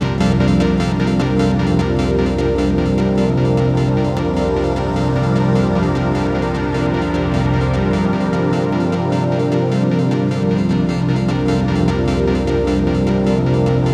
Index of /90_sSampleCDs/USB Soundscan vol.13 - Ethereal Atmosphere [AKAI] 1CD/Partition A/08-SEQ PAD A
SEQ PAD01.-R.wav